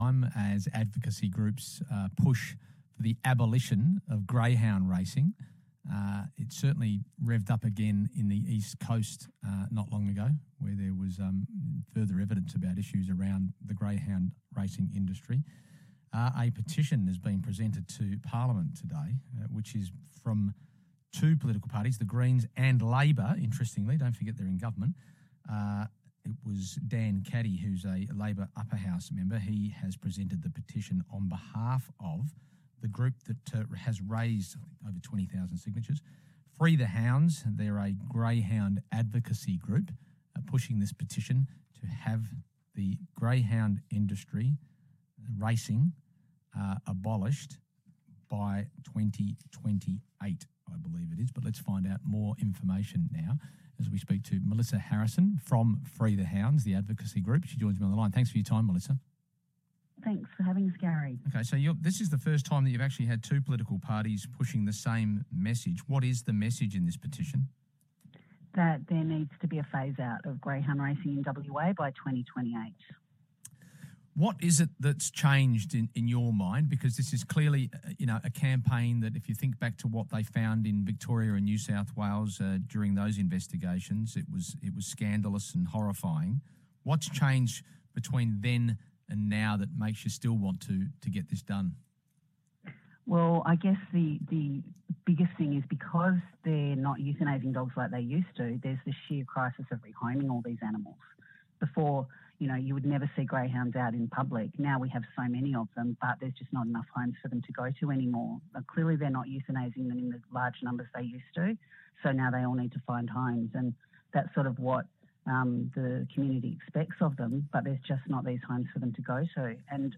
Presenter